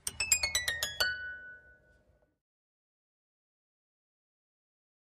Harp, Slow, Short And High Strings Descending, Type 1